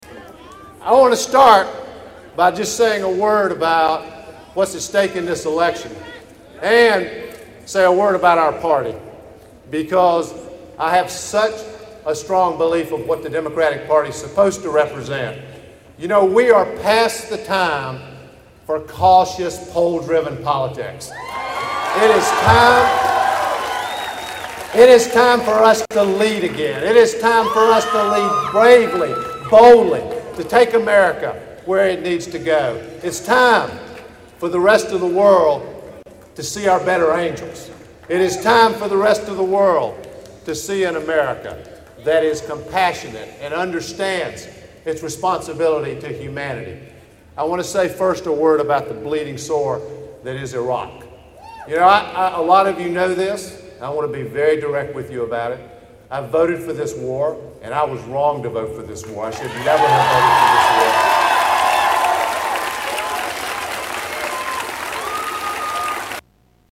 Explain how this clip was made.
Democratic Convention Speech